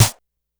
Snare_26.wav